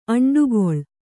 ♪ aṇḍugoḷ